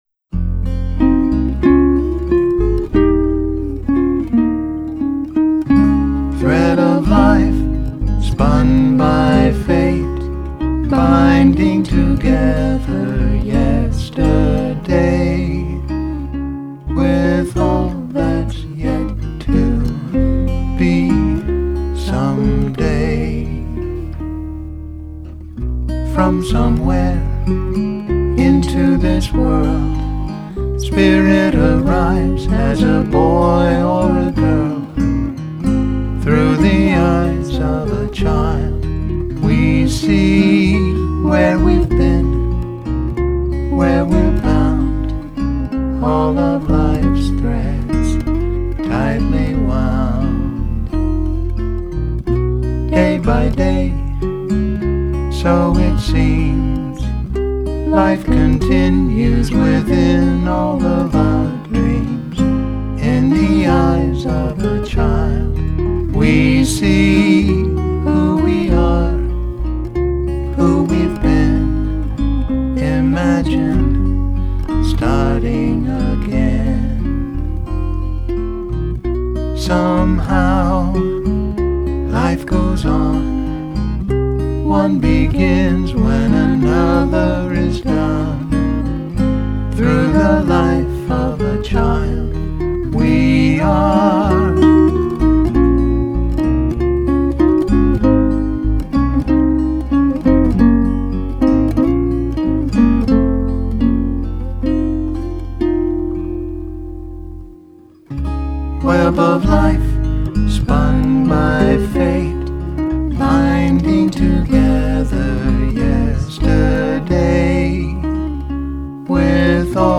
guitar and vocals